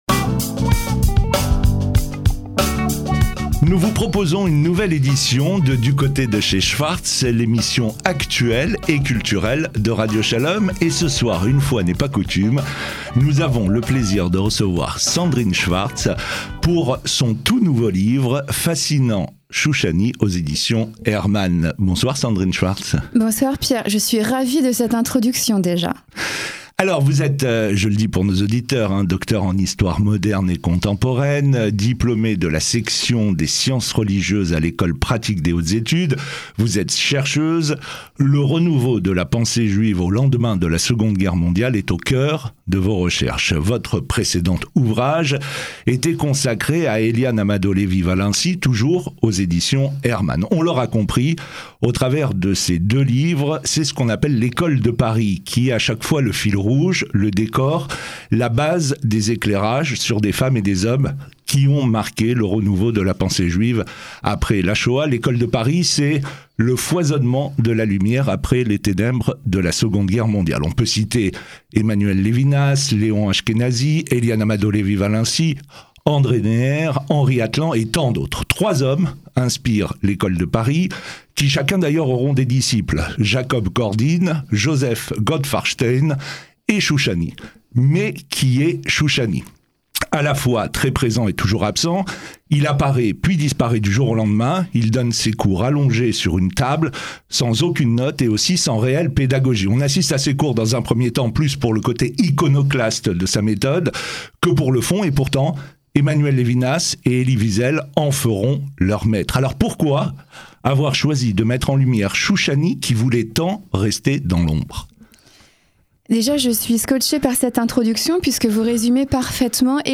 L’intervieweuse interviewée !